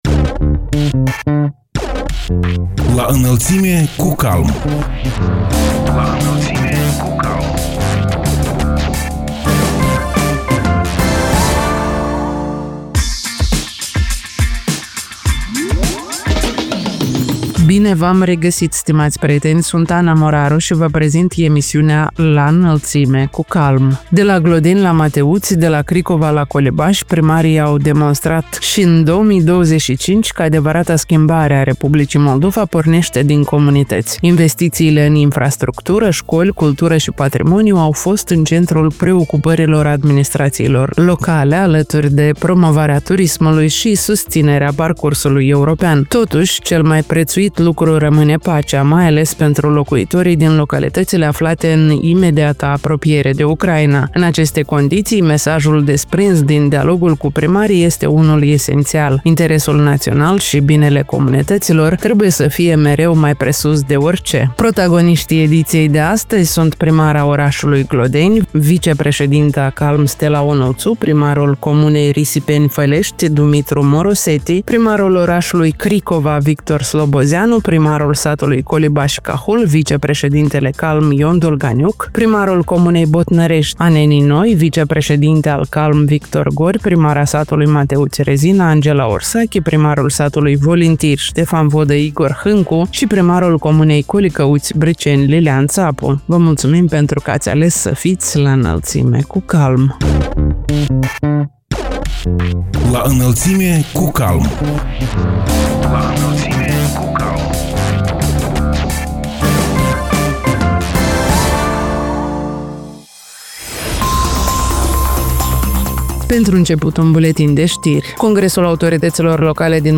Protagoniștii emisiunii „La Înălțime cu CALM” sunt: Stela Onuțu, primara orașului Glodeni, vicepreședintă a CALM; Dumitru Mosoreti, primarul comunei Risipeni, Fălești; Victor Slobozianu, primarul orașului Cricova; Ion Dolganiuc, primarul satului Colibași, Cahul, vicepreședinte al CALM; Victor Gori, primarul comunei Botnărești, Anenii Noi, vicepreședinte al CALM; Angela Ursachi, primara satului Mateuți, Rezina; Igor Hîncu, primarul satului Volintiri, Ștefan Vodă; Lilian Țapu, primarul comunei Colicăuți, Briceni.